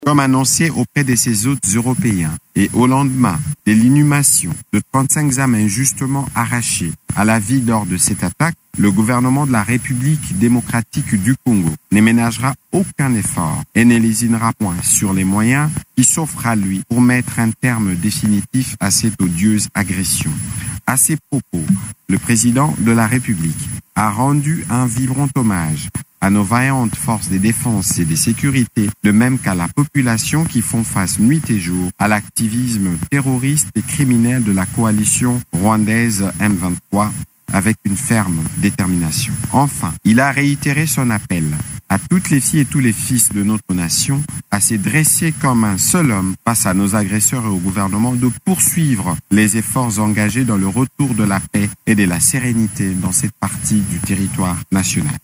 Vous pouvez écouter un extrait du conseil des ministres lu par Patrick Muyaya, ministre de la Communication et des médias dans cet extrait :